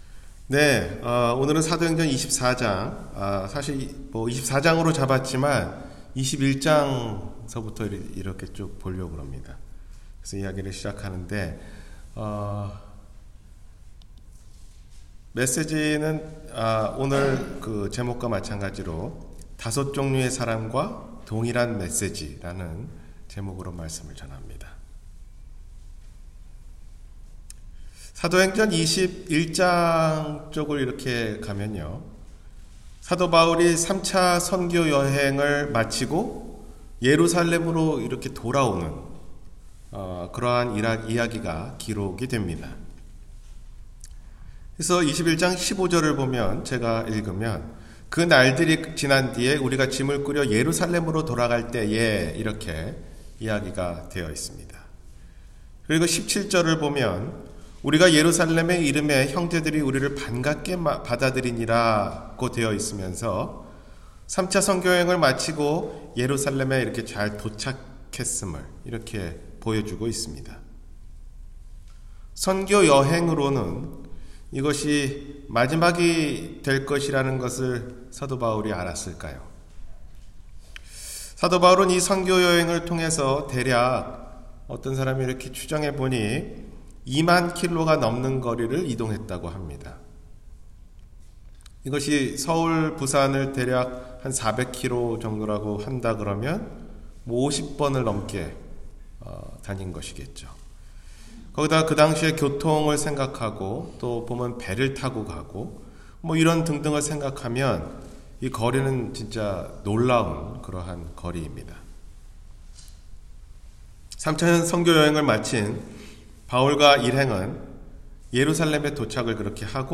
다섯 부류의 사람과 한 종류의 메세지 – 주일설교 – 갈보리사랑침례교회